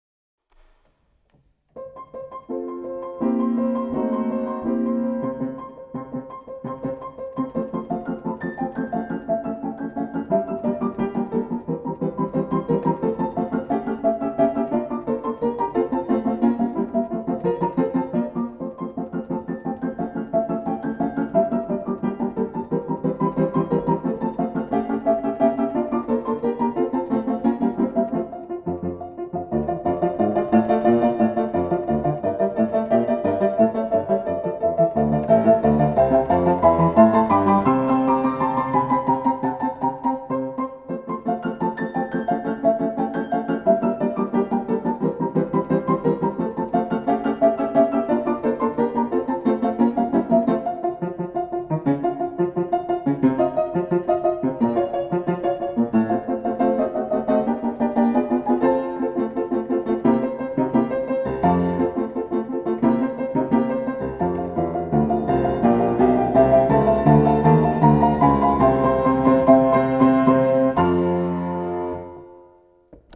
ピアノコンサート２
自宅のGPによる演奏録音 　　 デジピによる演奏録音